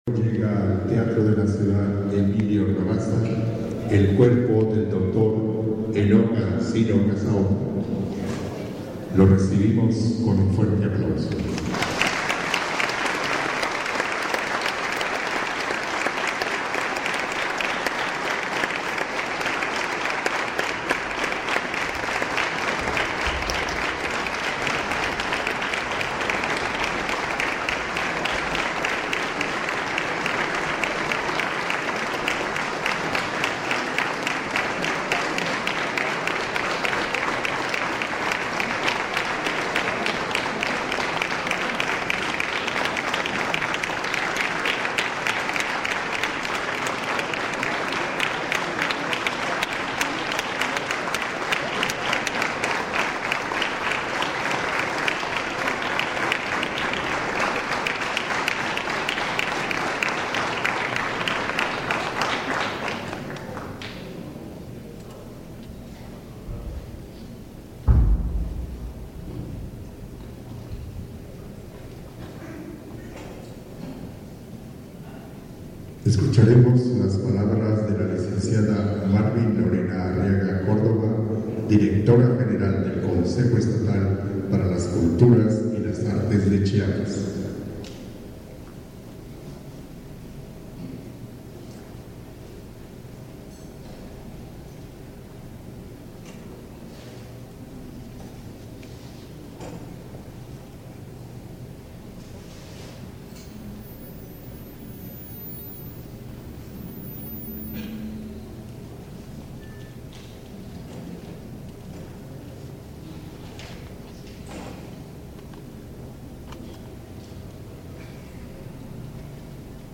Lugar: Tuxtla Gutierrez, Chiapas; Mexico.
Equipo: Grabadora Sony ICD-UX80 Stereo, Micrófono de construcción casera ( más info ) Fecha: 2012-02-06 11:51:00 Regresar al índice principal | Acerca de Archivosonoro